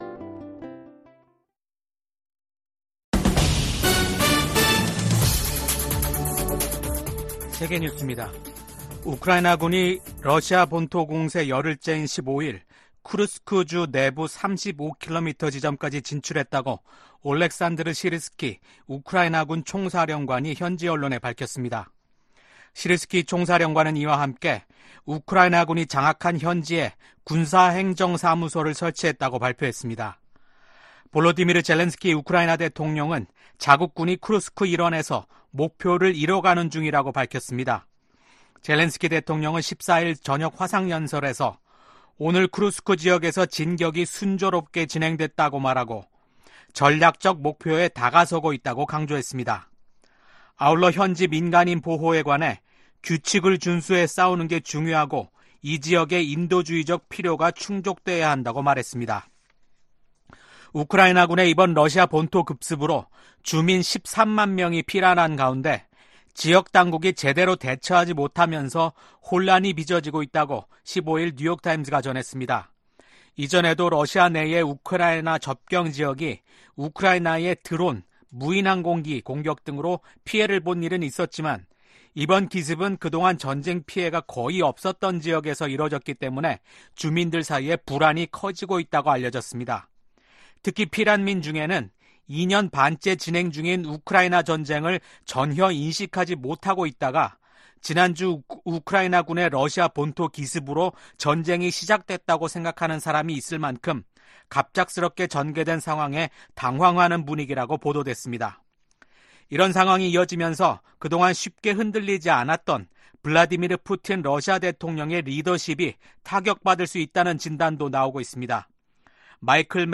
VOA 한국어 아침 뉴스 프로그램 '워싱턴 뉴스 광장' 2024년 8월 16일 방송입니다. 조 바이든 미국 대통령은 퇴임 의사를 밝힌 기시다 후미오 일본 총리가 미한일 협력 강화에 기여했다고 평가했습니다. 윤석열 한국 대통령은 광복절을 맞아 자유에 기반한 남북한 통일 구상과 전략을 ‘독트린’이라는 형식으로 발표했습니다. 북한이 2018년 넘긴 55개 유해 상자에서 지금까지 미군 93명의 신원을 확인했다고 미국 국방부 당국자가 말했습니다.